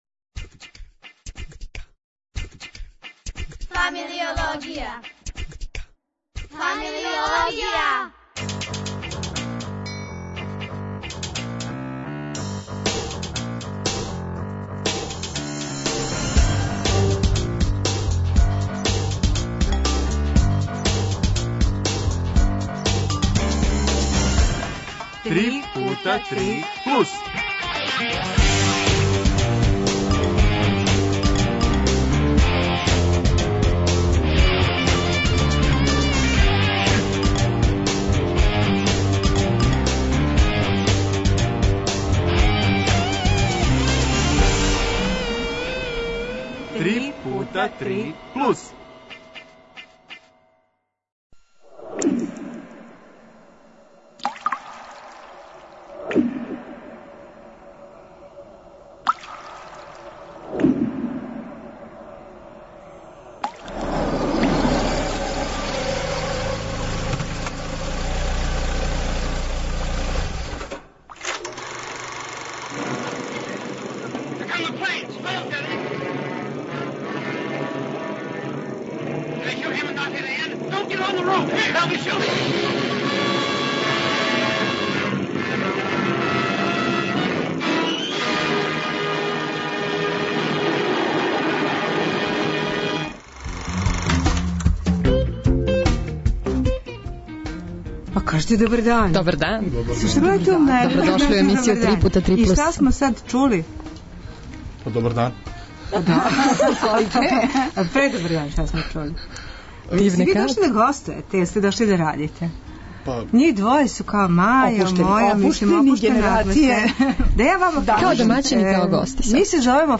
И тај дан је дошао: имаћемо (живе) кућне љубимце у студију 1! Два (додуше мала) пса, са својим власницама.